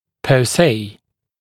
[ˌpɜː’seɪ][ˌпё:’сэй]само по себе; по сути, непосредственно